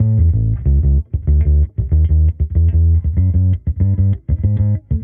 Index of /musicradar/sampled-funk-soul-samples/95bpm/Bass
SSF_PBassProc1_95A.wav